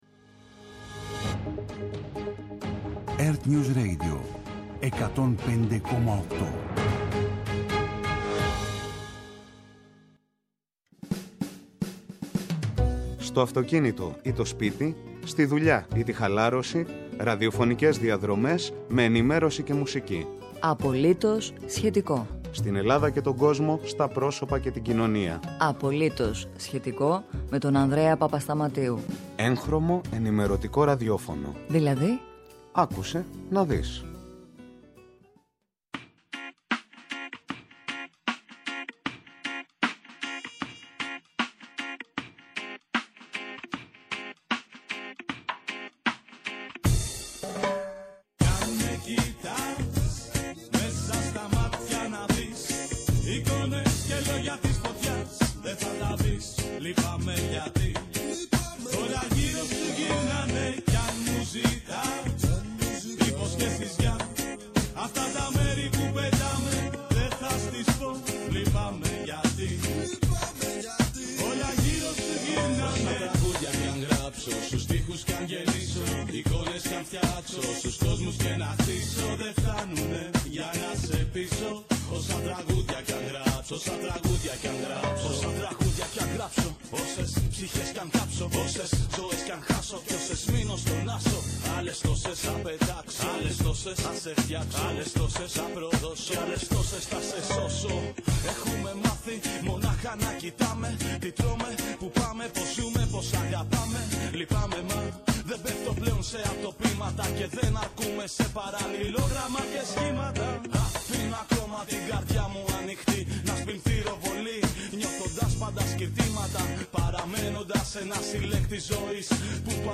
-Ο Δήμαρχος Κερατσινίου – Δραπετσώνας Χρήστος Βρεττάκος για την καθιερωμένη πορεία στη μνήμη του Παύλου Φύσσα, που ειναι σε εξέλιξη αυτή την ώρα